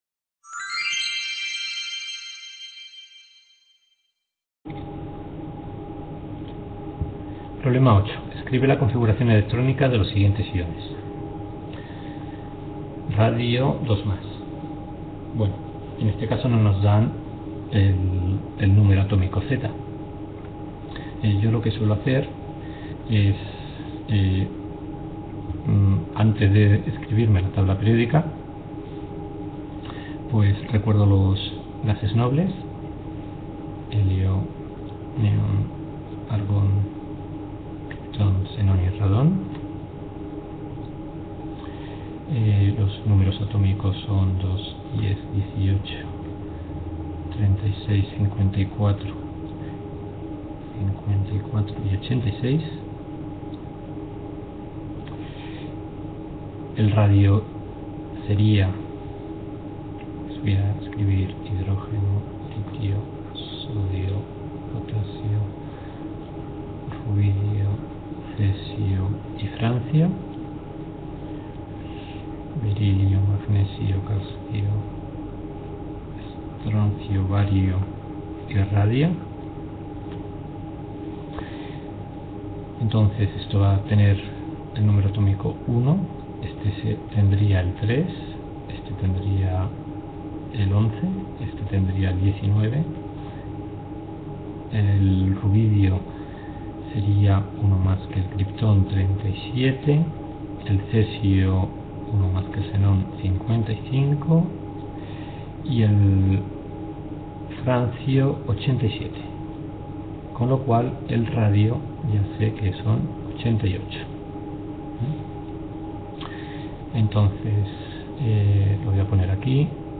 Video Clase
Tutoría (Enseñanza)